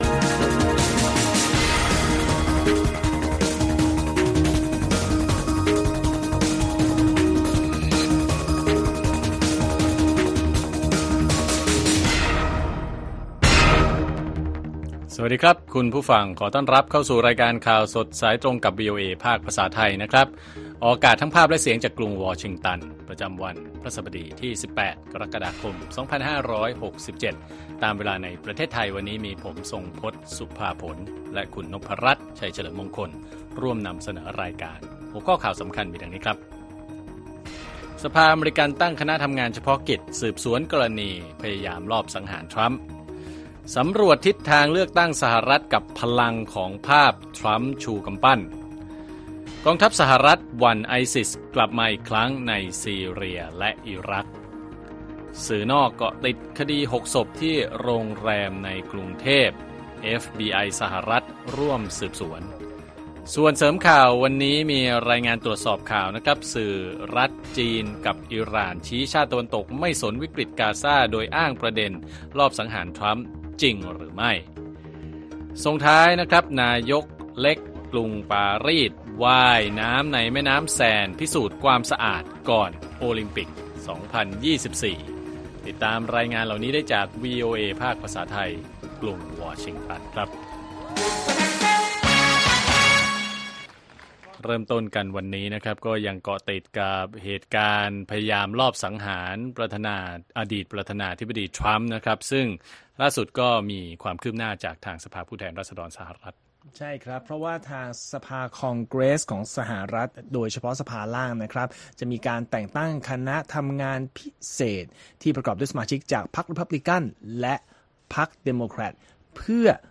ข่าวสดสายตรงจากวีโอเอ ไทย ประจำวันที่ 18 กรกฎาคม 2567